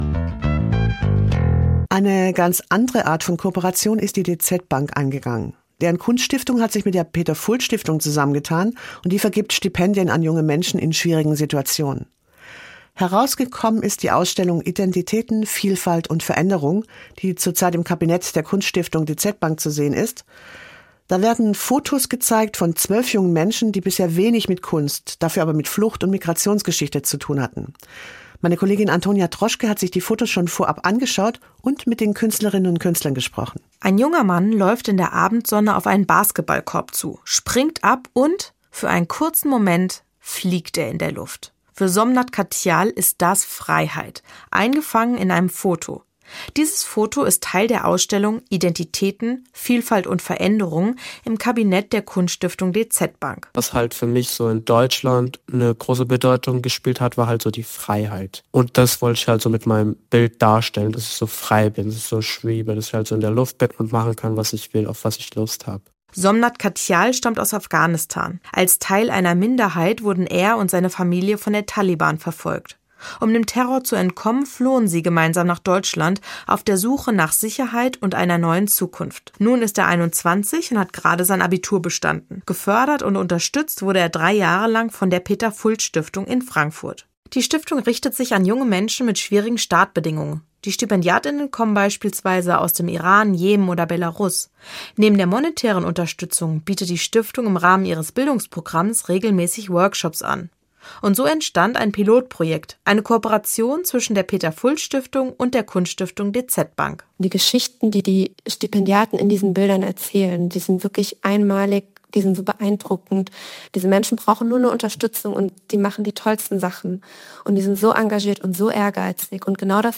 Im Interview mit dem hr! Identiäten. Vielfalt und Veränderung